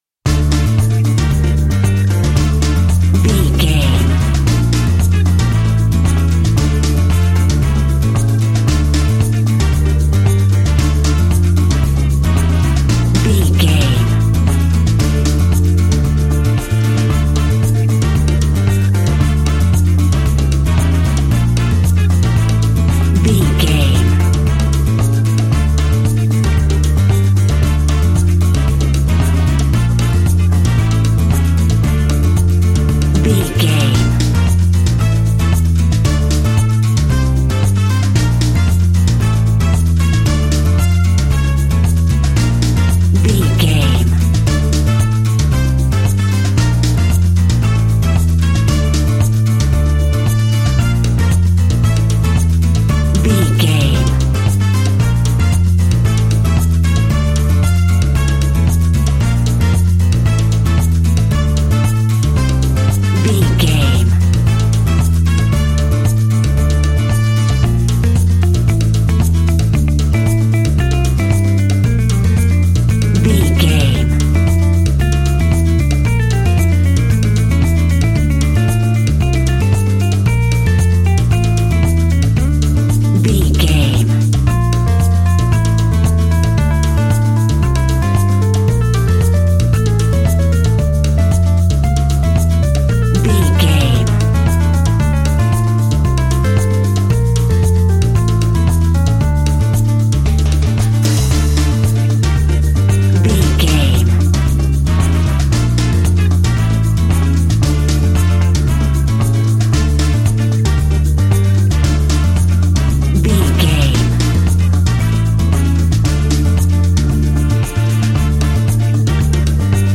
An exotic and colorful piece of Espanic and Latin music.
Aeolian/Minor
flamenco
maracas
percussion spanish guitar